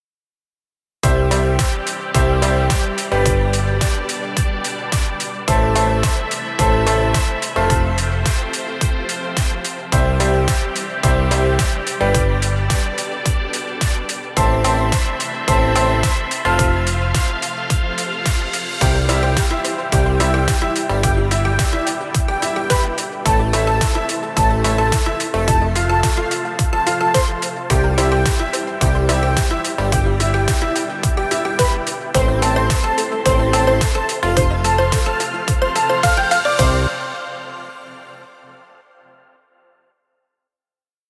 Dance music for video.